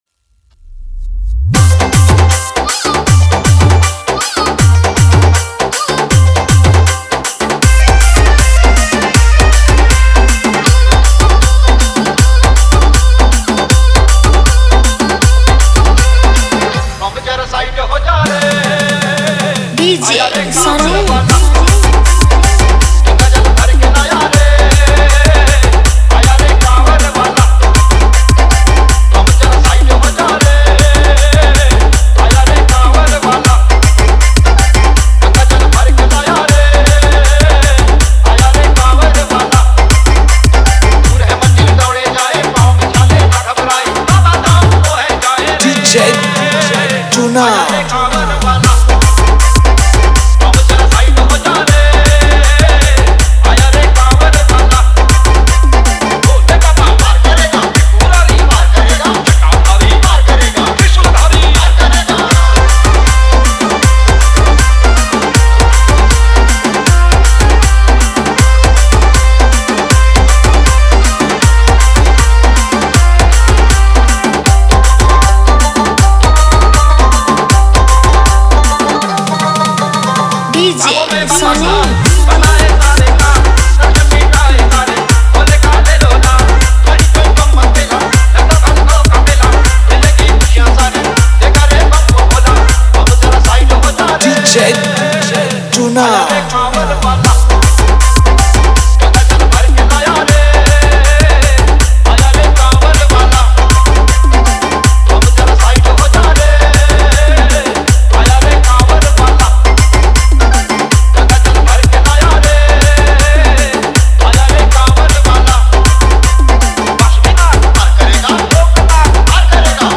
Bolbum Special Dj Song Songs Download